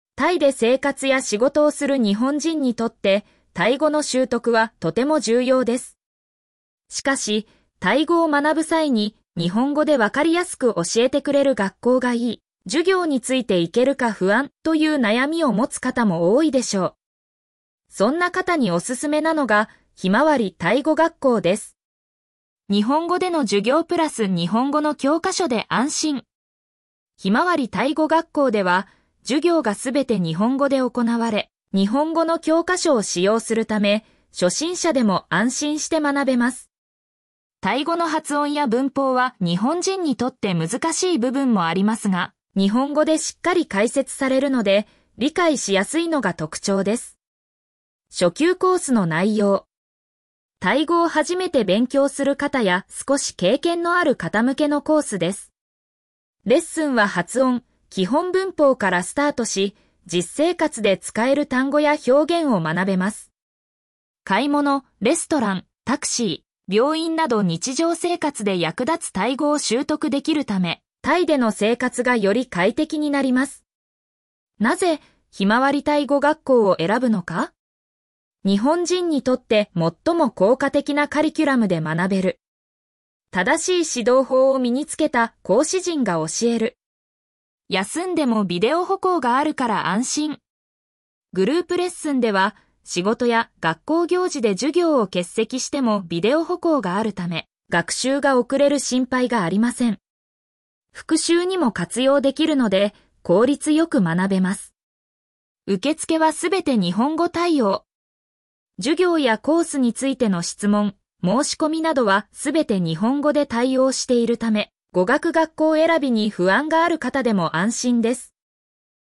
ペルプ バンコク・メディプレックスビルディング リンク先 「ひまわりタイ語学校」授業風景 グループ 「ひまわりタイ語学校」授業風景 個人 読み上げ タイで生活や仕事をする日本人にとって、タイ語の習得はとても重要です。